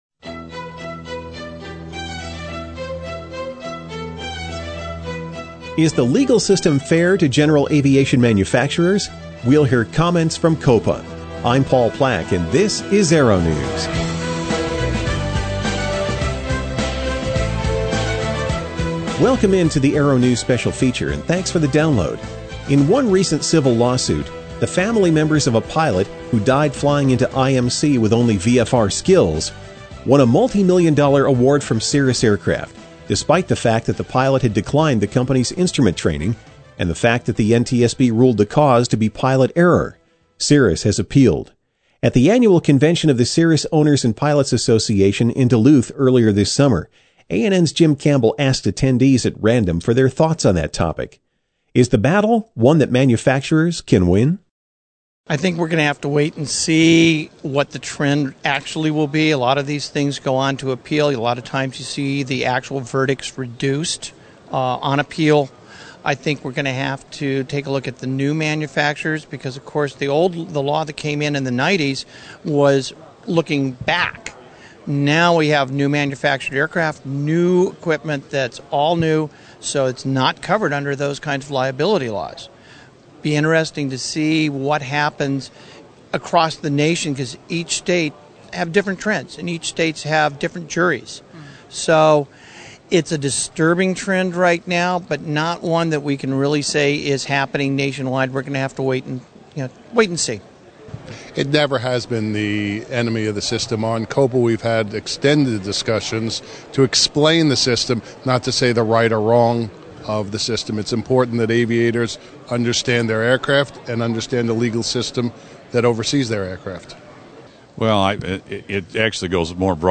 ANN Daily Aero-Briefing: 08.20.09 (ANN's Long-Form Daily News Program)